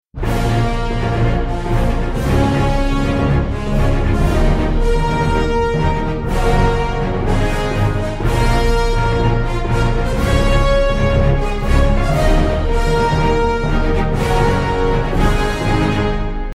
Супергеройская музыка без ап